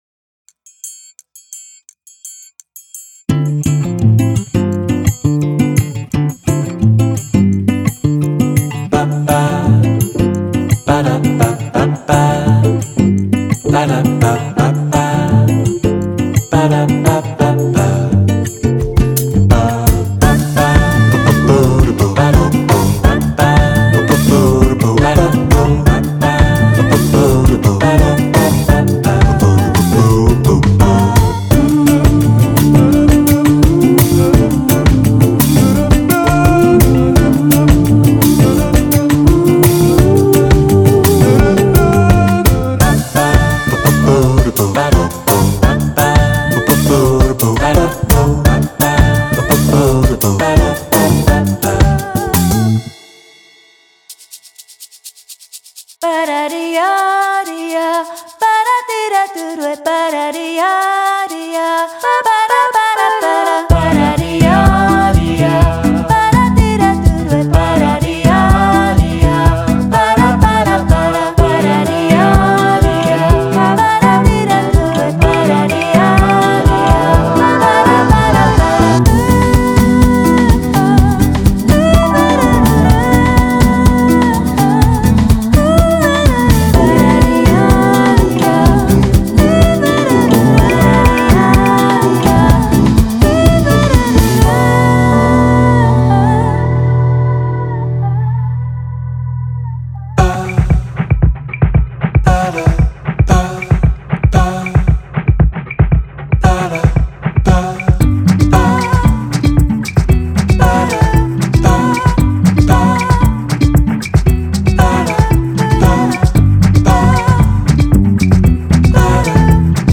ボサノヴァ音楽に特化した心躍るコーラスボーカルのコレクションです。
デモサウンドはコチラ↓
Genre:Vocals
47 Female Adlib Vocal Loops
25 Male Melisma Loops
25 Female Rhythm Vocal Loops